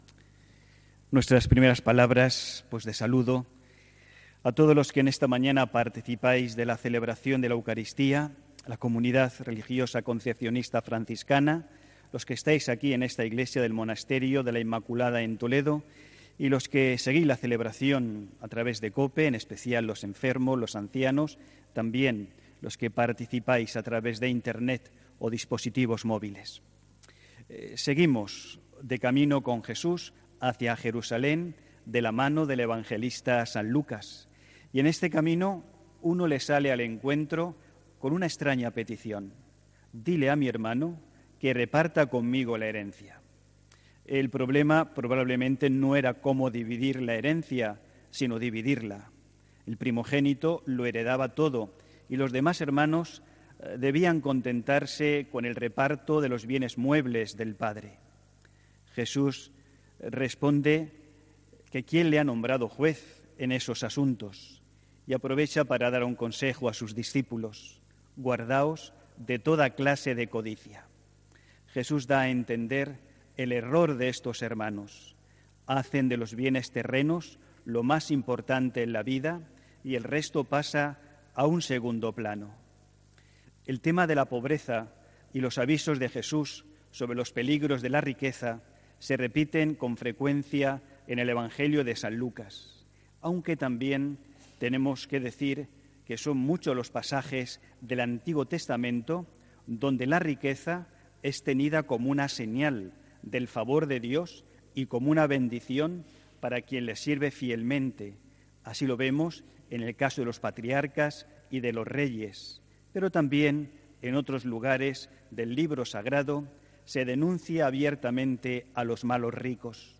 HOMILÍA 4 AGOSTO 2019